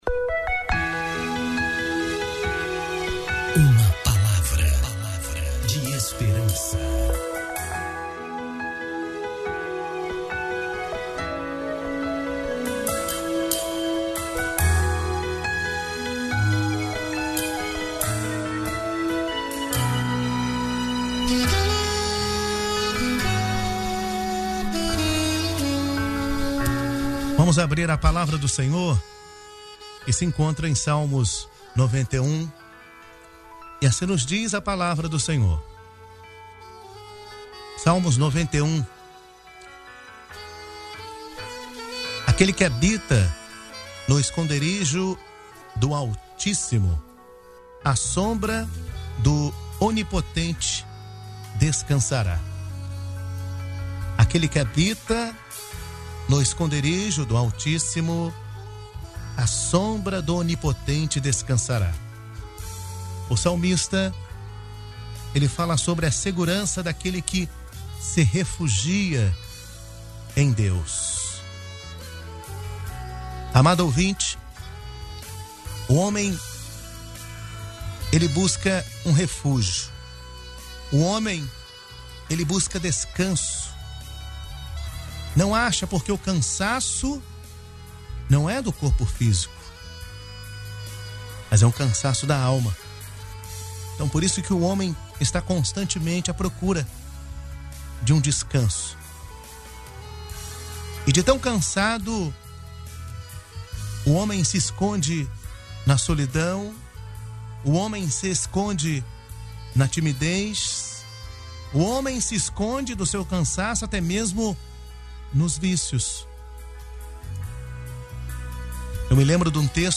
Mensagem transmitida no dia 14 de setembro de 2020, dentro do programa Boa Tarde Maanaim